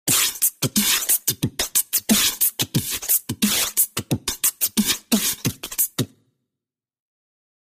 Human Beat Box, R&B Rhythm, Type 1